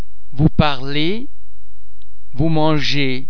The French [z] sound is normally pronounced [z] as in the English words zero, zebra etc.
·BUT watch ! the [-ez] of verbs